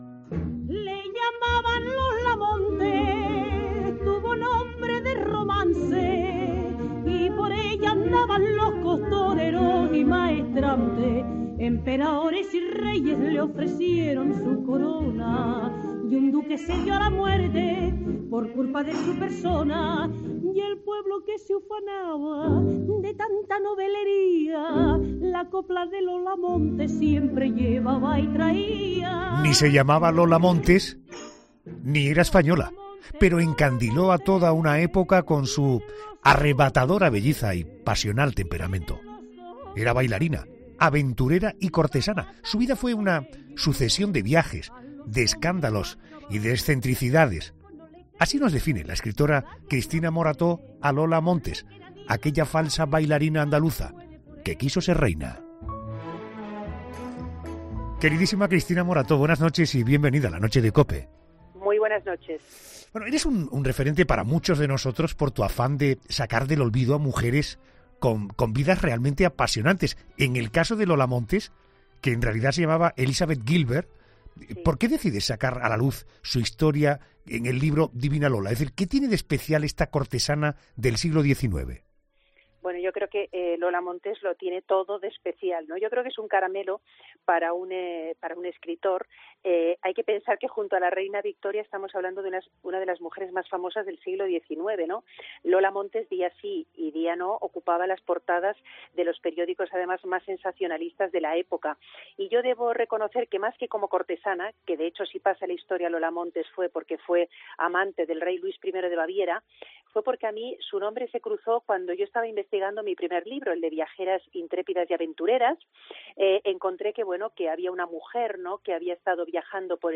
Cristina Morató cuenta la historia de Lola Montes, una de las mujeres más famosas del siglo XIX